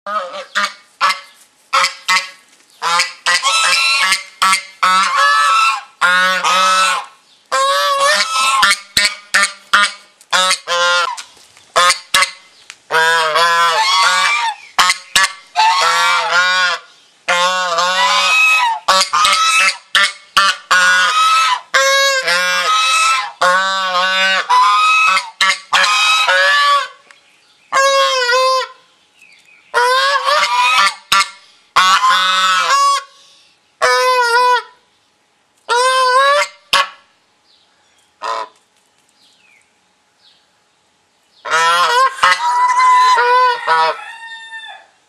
• They have also been considered good "watch-dogs";, as they can be quite aggressive and vocal when threatened.
Bird Sound
Loud honking noise.
ChineseGeese.mp3